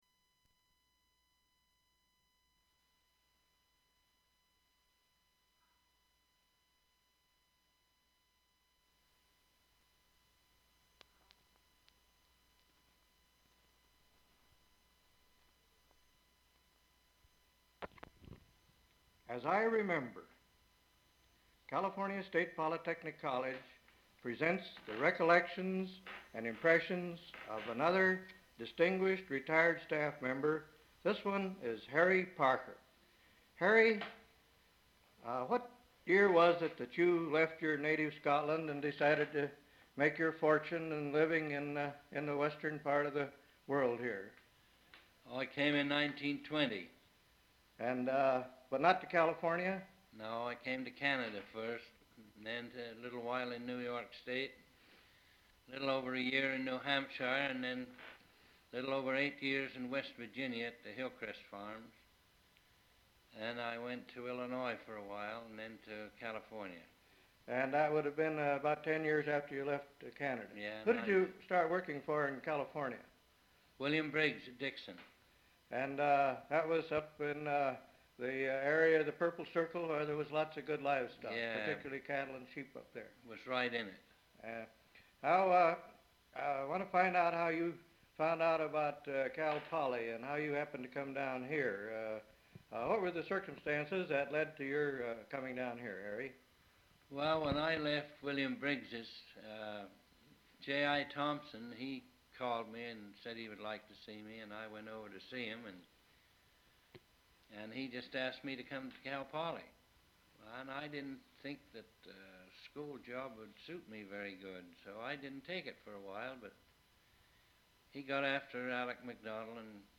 Open reel audiotape